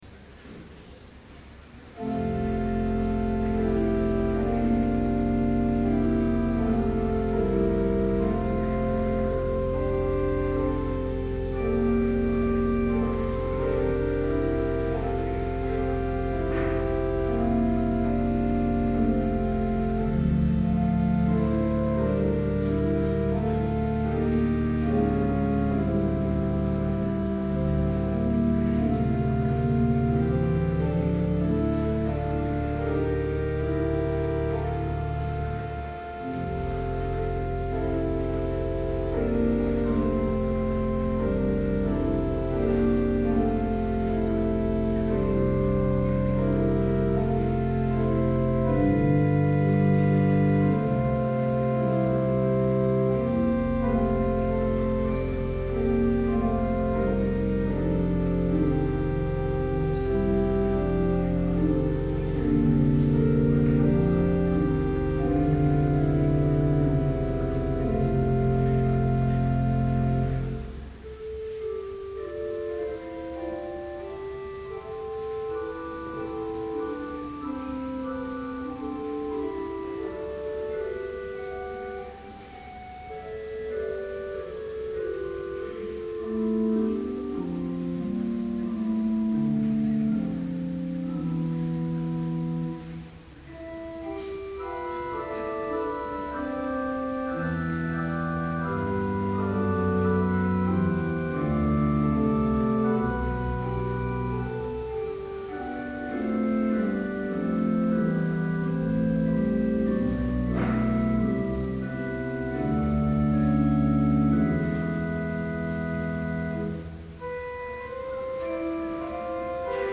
Complete service audio for Lenten Vespers - February 22, 2012